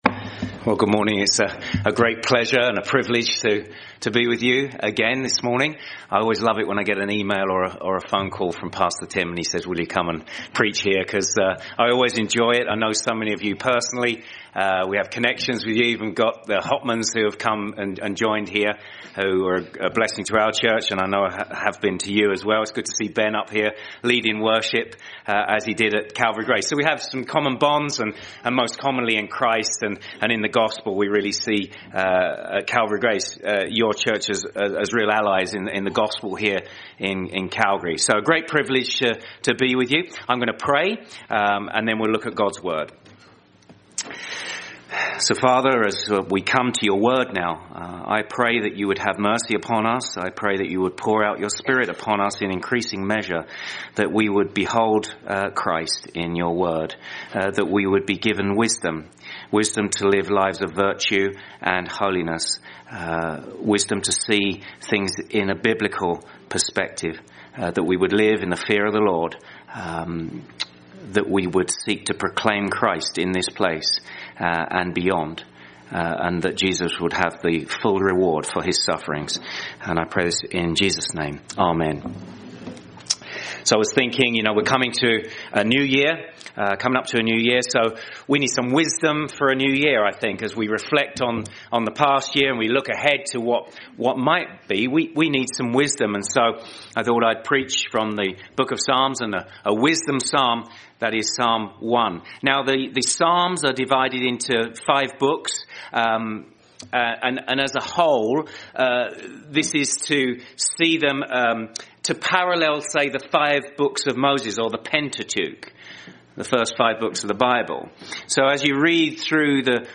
Sermon: True Happiness